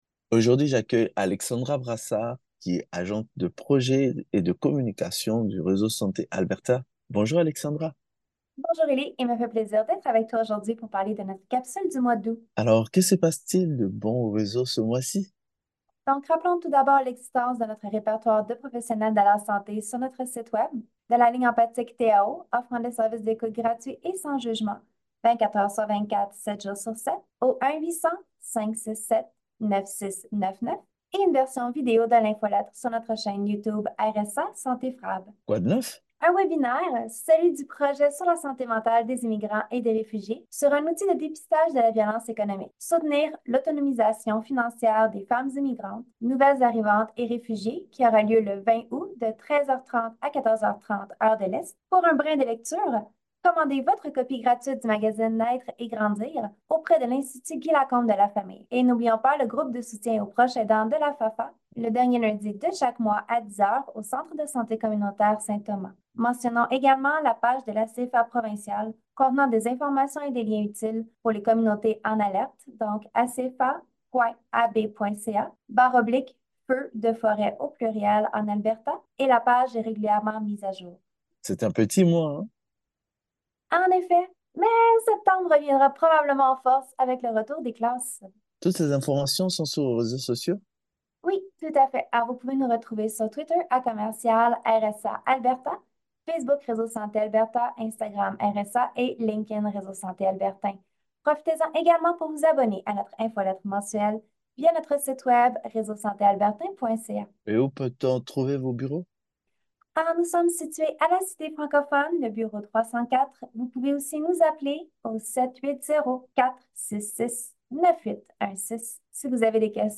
Notre journaliste